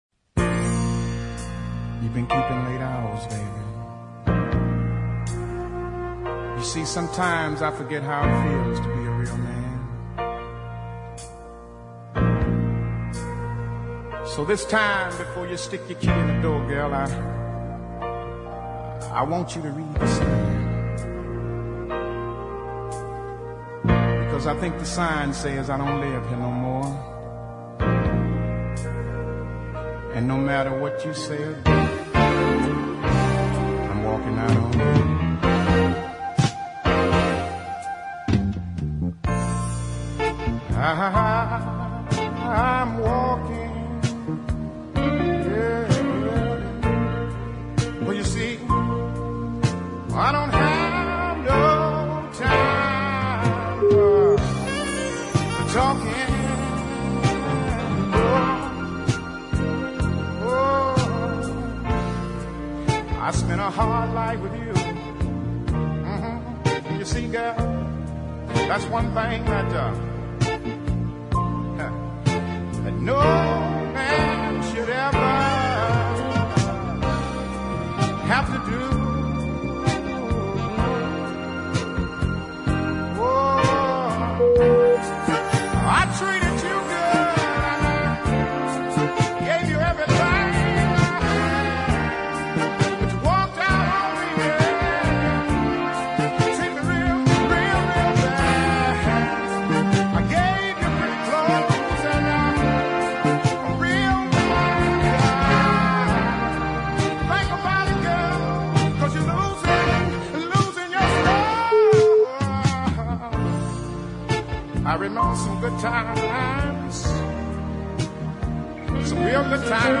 some nice chord changes
This was recorded in Pearl, MS
Deep soul hadn't died by 1980 it just went underground.